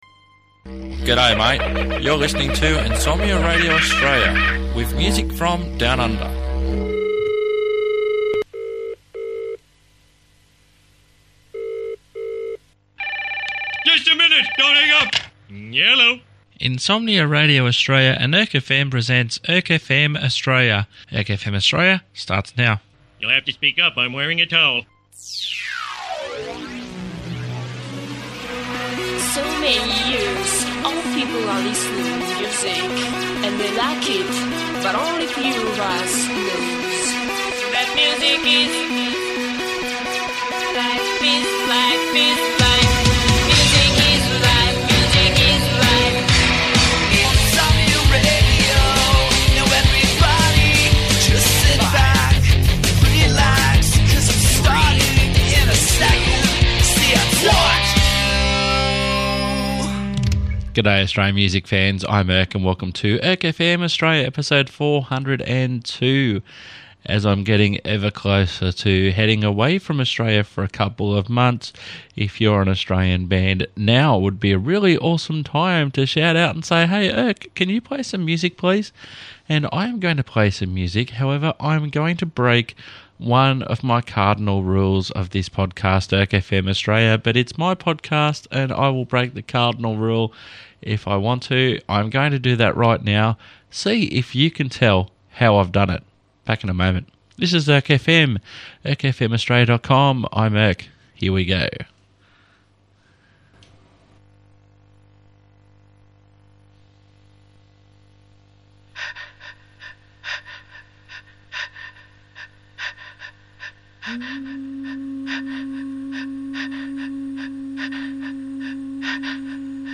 After that, there's a multi-genre selection of great Australian tunes.